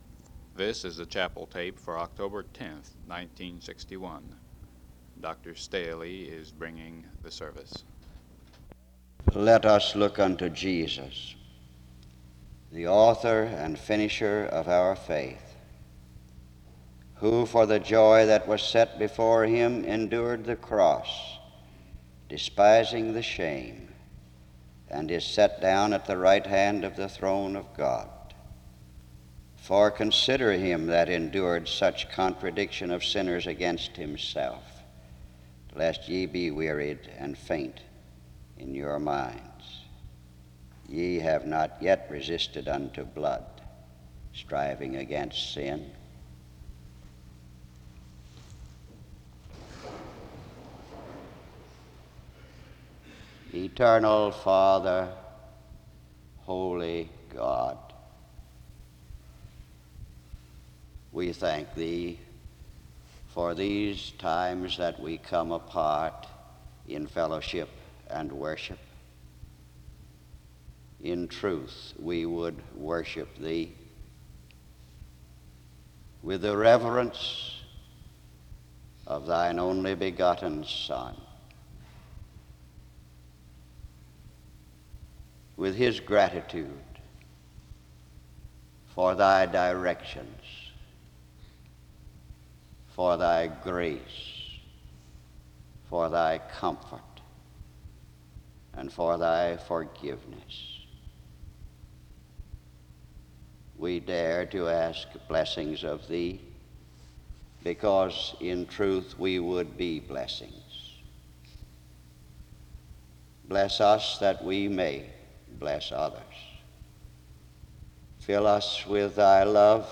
He prays from 0:53-5:27.
SEBTS Chapel and Special Event Recordings SEBTS Chapel and Special Event Recordings